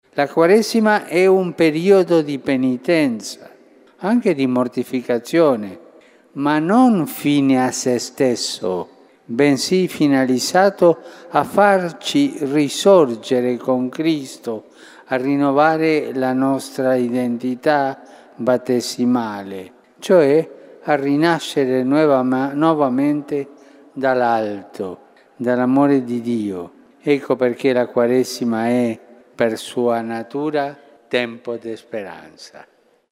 O Wielkim Poście, jako pielgrzymce nadziei, mówił papież Franciszek podczas audiencji ogólnej.